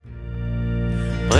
Der Sound, die Musik ist pfeilgerade und präzise.